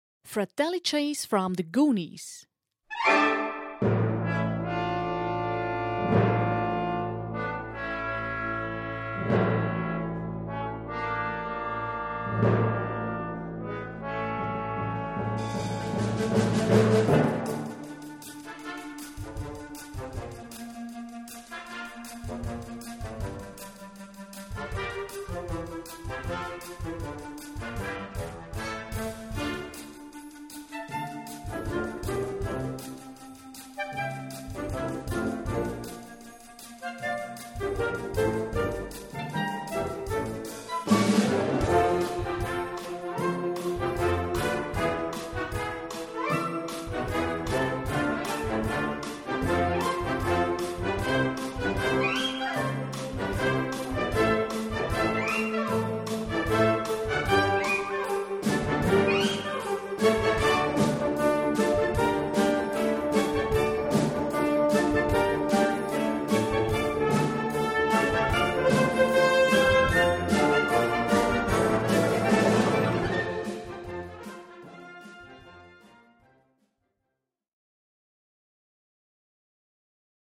Gattung: Filmmusik
Besetzung: Blasorchester
Unterhaltsam und abwechselnd in jedem Konzertprogramm.